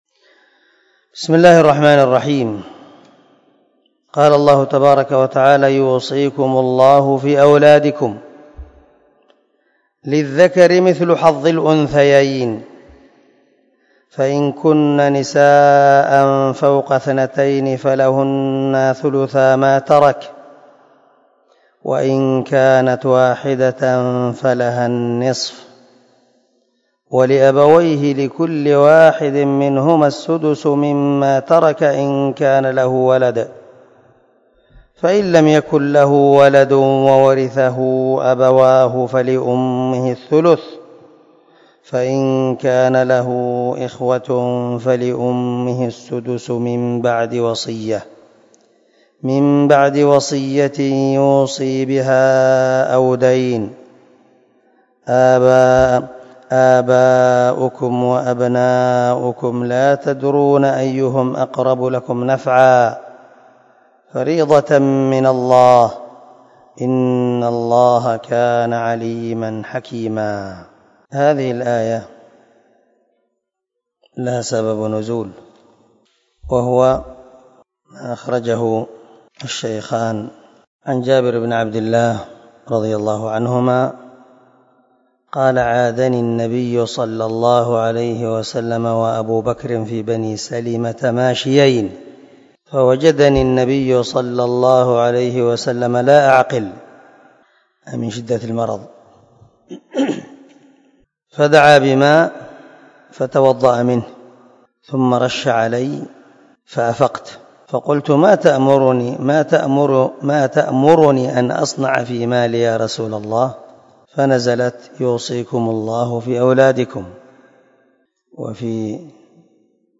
240الدرس 8 تفسير آية ( 11 ) من سورة النساء من تفسير القران الكريم مع قراءة لتفسير السعدي